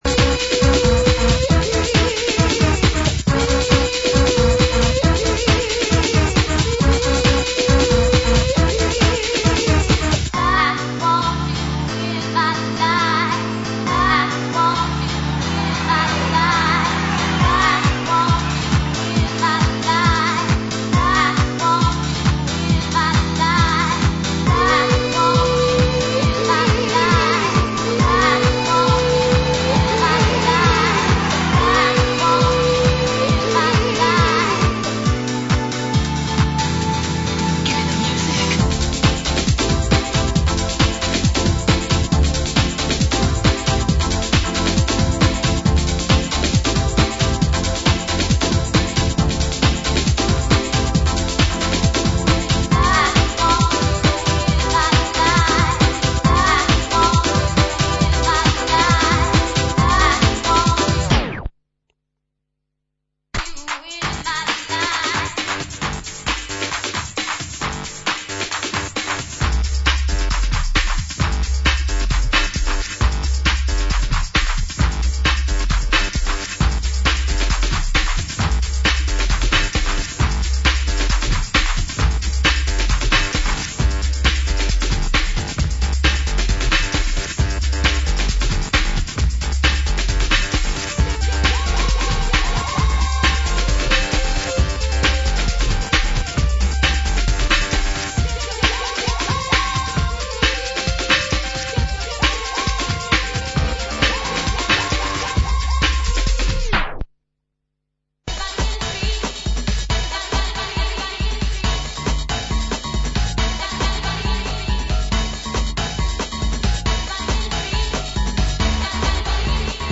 Old-Skool Breakbeat, Hardcore